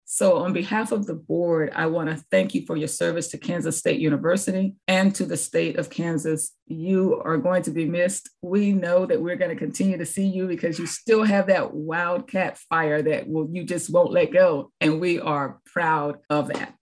Wednesday was also the final Board of Regents meeting for retiring K-State President Richard Myers, who was honored with a statement on behalf of the board from President Cheryl Harrison-Lee.